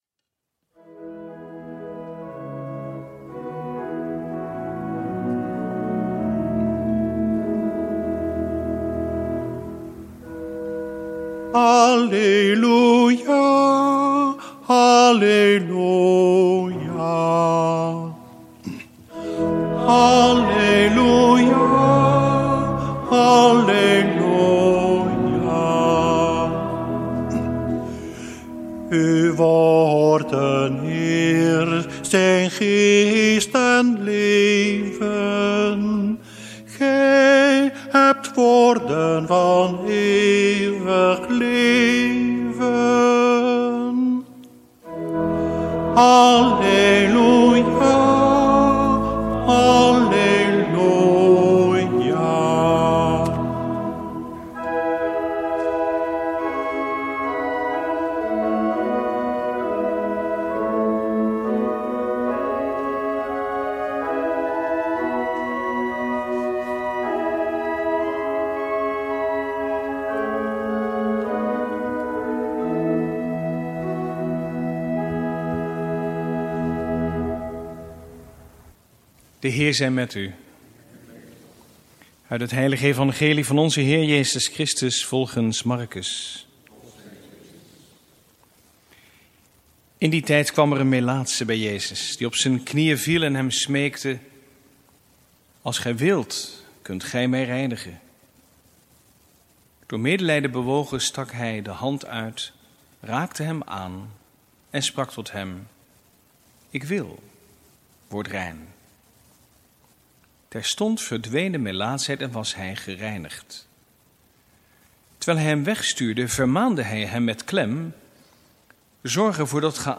Lezingen
Eucharistieviering beluisteren vanuit de H. Jozef te Wassenaar (MP3)